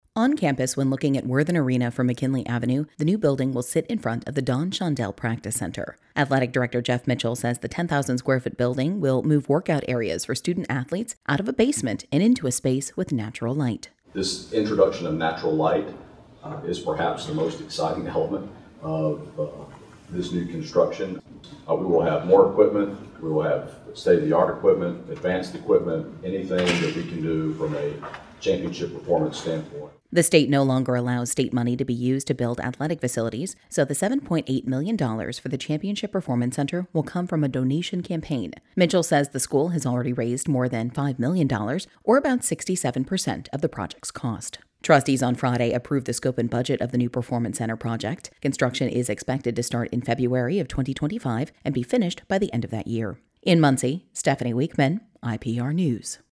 IPR News